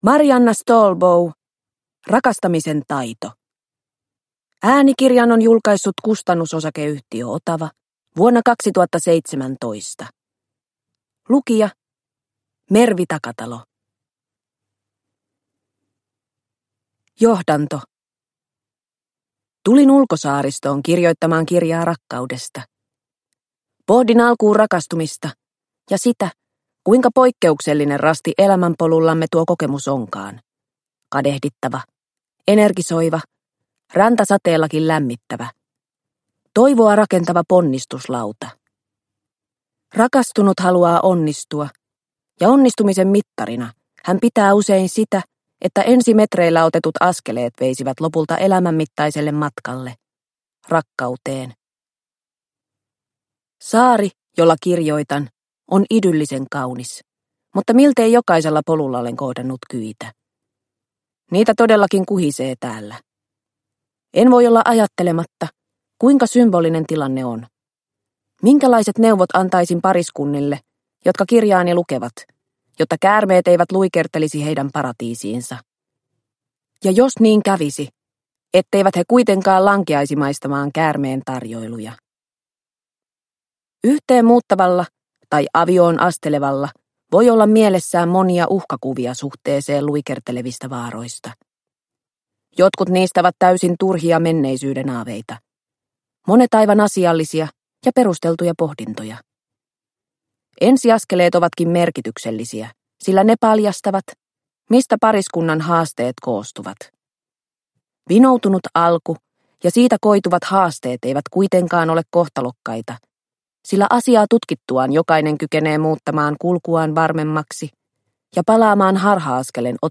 Rakastamisen taito – Ljudbok – Laddas ner